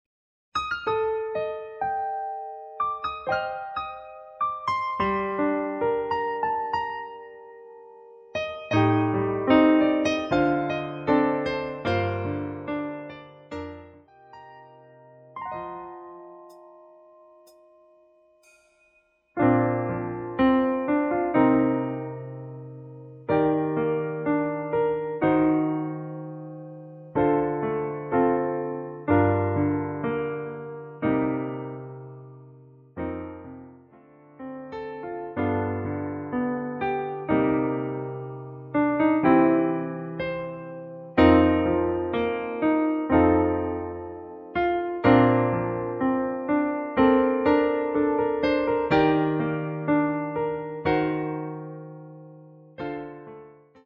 *노래 시작하기전 하이햇 소리 넣었습니다.
한키 올린 MR입니다.
피아노 반주만으로 리메이크한 곡
키 Bb
원곡의 보컬 목소리를 MR에 약하게 넣어서 제작한 MR이며